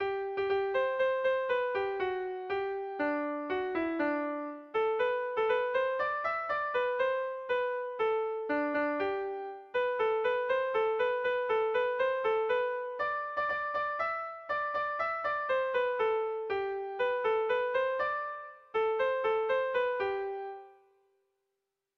Air de bertsos - Voir fiche   Pour savoir plus sur cette section
Irrizkoa
Donazaharre < Garazi < Baxenabarre < Euskal Herria
Hamarreko txikia (hg) / Bost puntuko txikia (ip)
AB1DEB2